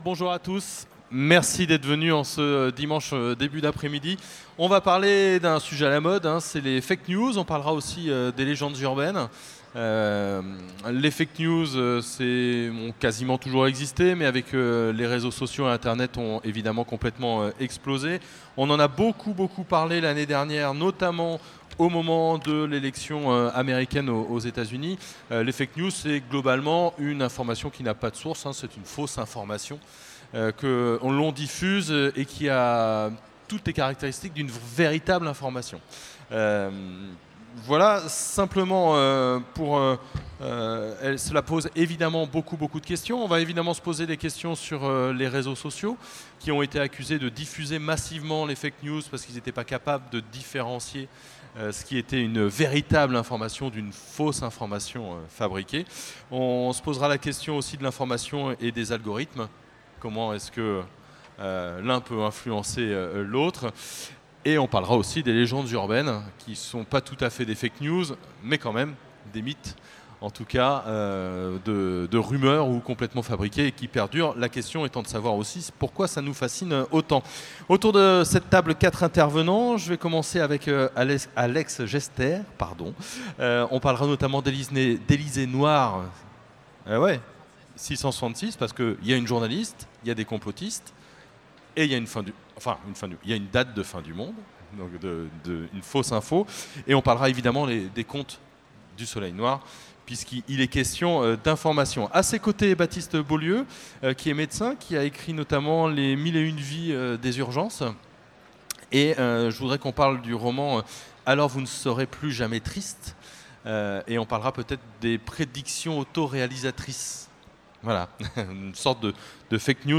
Utopiales 2017 : Conférence Fake news et légendes urbaines, le mensonge est-il extra-temporel ?